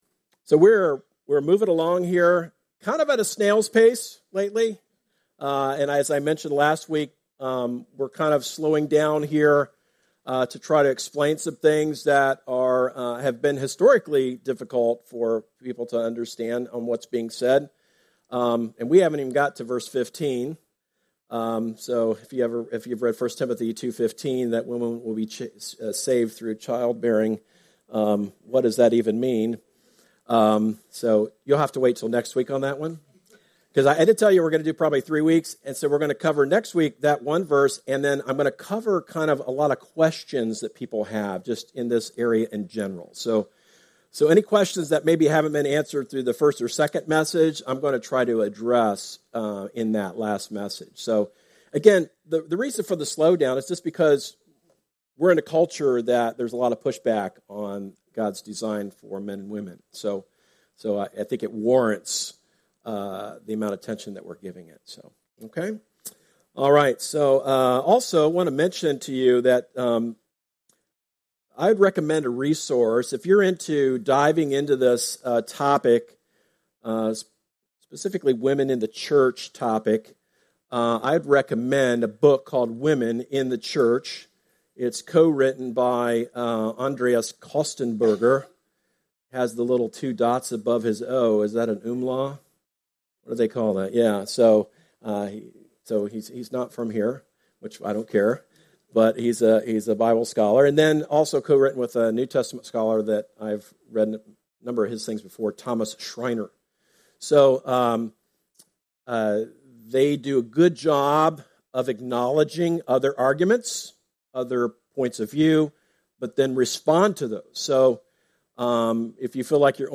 Sermons – Darby Creek Church – Galloway, OH